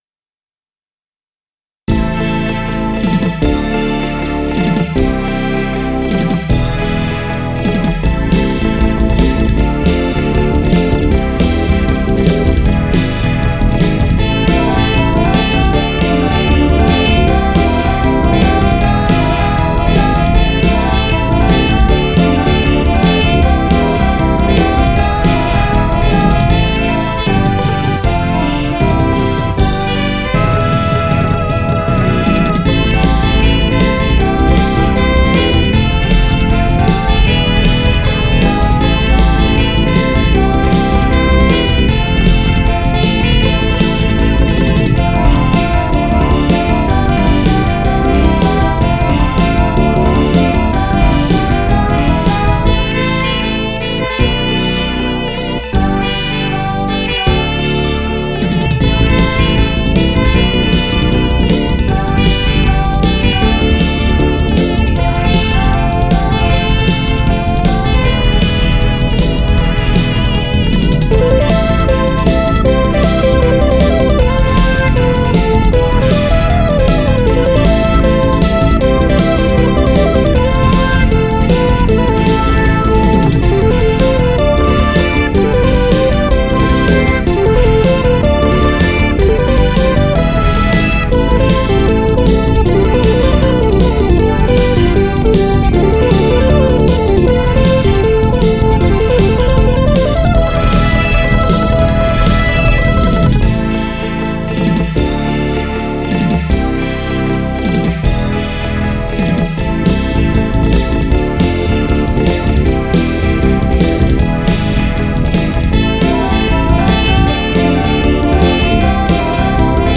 VOCALOID MEIKOの声を一音ずつ録音し、CronoX3に取り込んだものをメインメロディに使用。